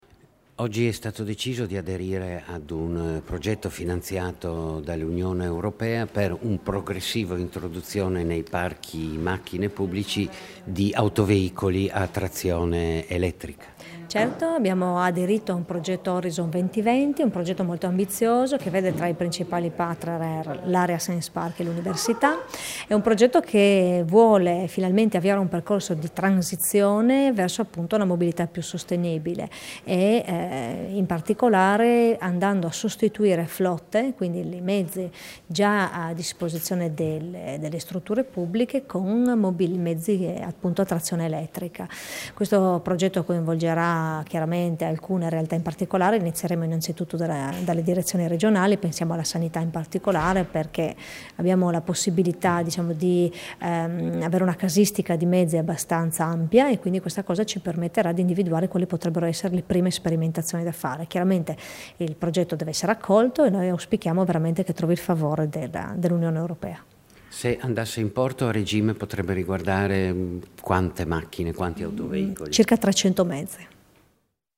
Dichiarazioni di Sara Vito (Formato MP3)
sul Progetto NeMo FVG - New Mobility in FVG, rilasciate a Pordenone il 9 settembre 2016